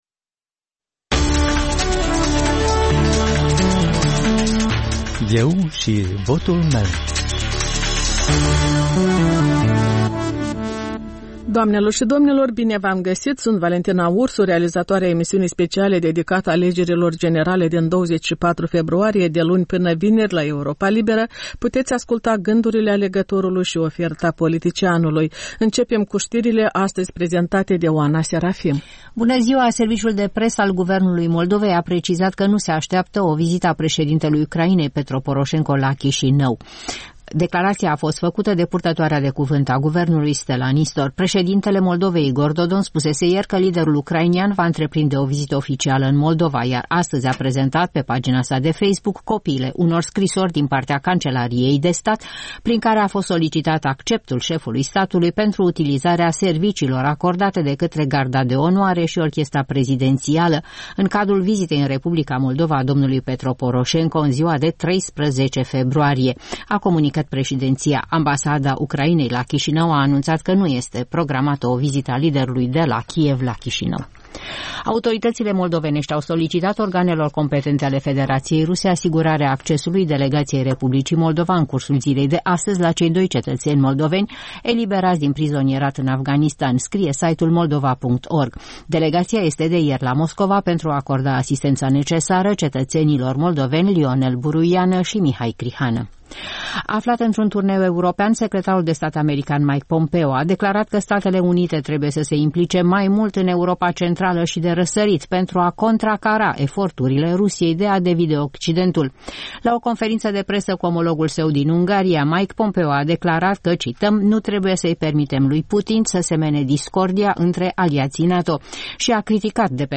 Analize, interviuri cu candidați și cu potențiali alegători, sondaje de opinie, reportaje din provincie.